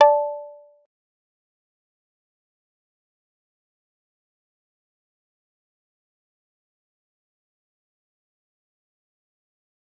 G_Kalimba-D5-pp.wav